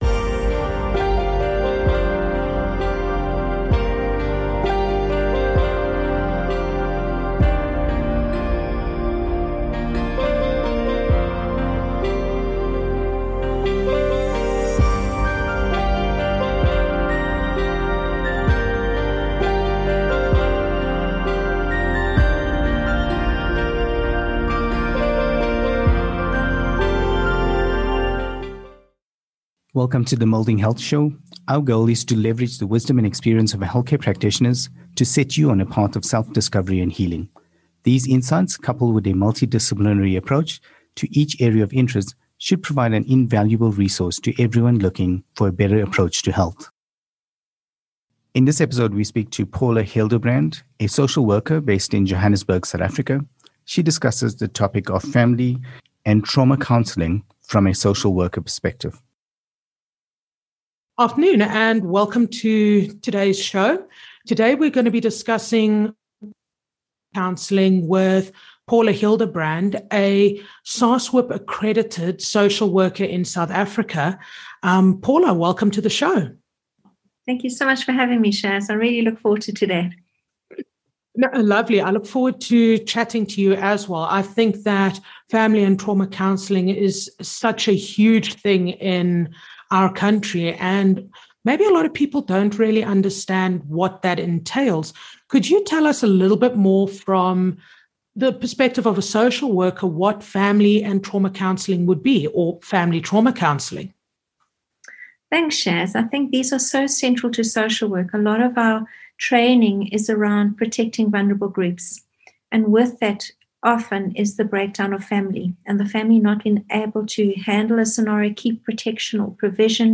Discussing Family and Trauma Counselling with a Social Worker